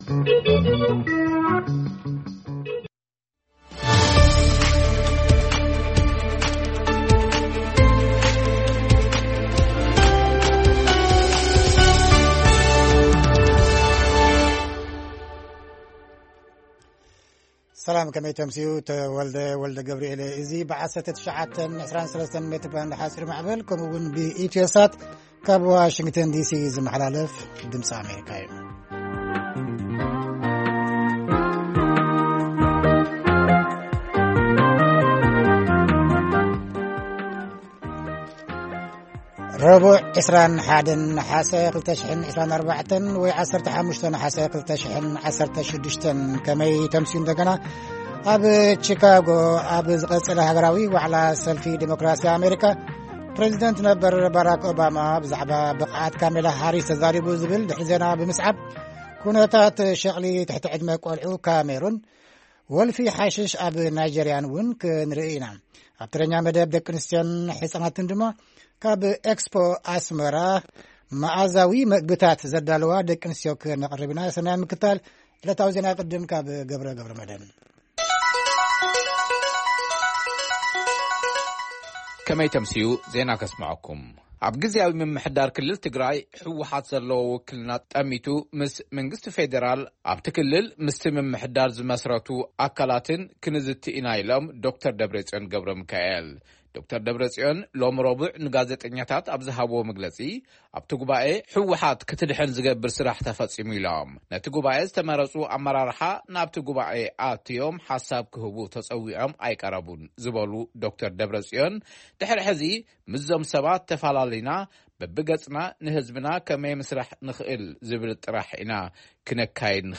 ፈነወ ትግርኛ ብናይ`ዚ መዓልቲ ዓበይቲ ዜና ይጅምር ። ካብ ኤርትራን ኢትዮጵያን ዝረኽቦም ቃለ-መጠይቓትን ሰሙናዊ መደባትን ድማ የስዕብ ። ሰሙናዊ መደባት ረቡዕ፡ ህዝቢ ምስ ህዝቢ